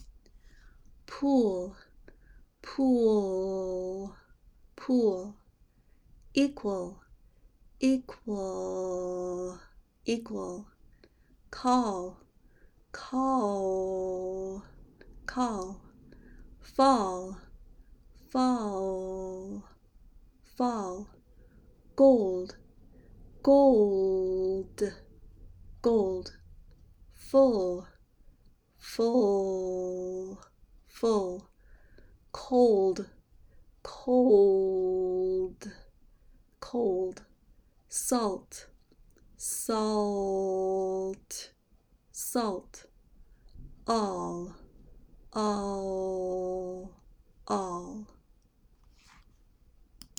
Here are some words containing dark l. Think about lowering the back of your tongue as you say them with me:
Practice these words with dark L
dark-l-words.mp3